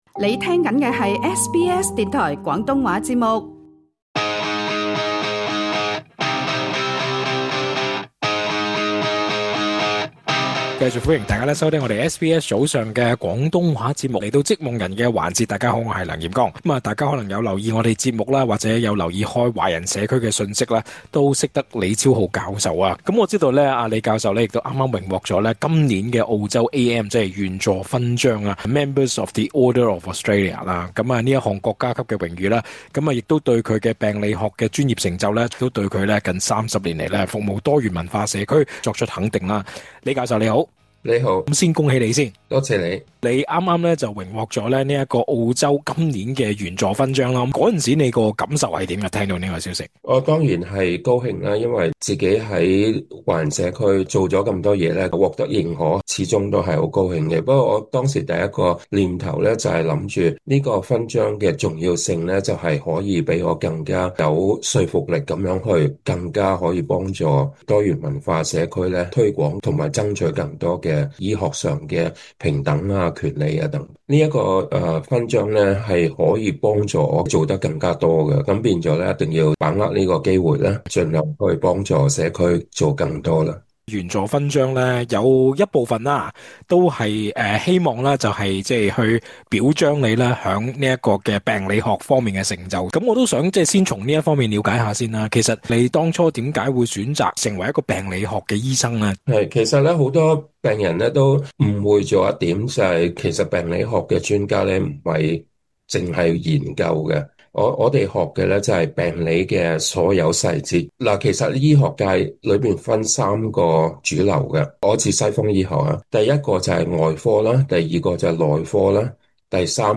完整訪問